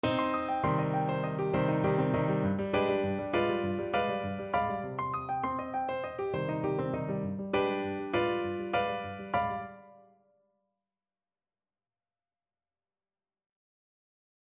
MIDI Liebmann, Hélène Riese, Grand Sonata for piano, Op.3, mvt. I. Allegro moderato, mm. 12-15